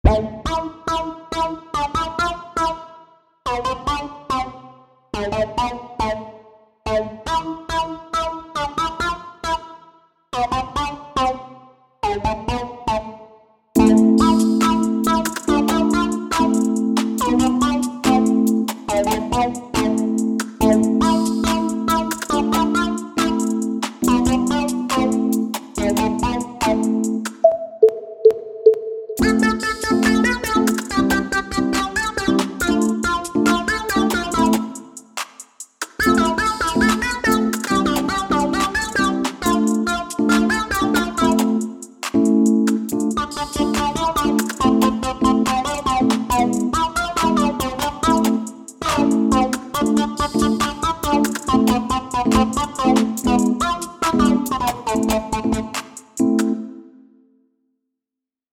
אחרי זמן שלמדתי את התוכנה- שמח לשתף אותכם בקטע קצר של נגינה האקורדים פשוטים יחסית אבל תנו אוזן לשילוב של הכלים ביט פסנתר…mp3 אשמח להארות והערות…
הפסנתר ממש יפה רק התופים ששמת בכלל לא בקצב תגובה 1 תגובה אחרונה 0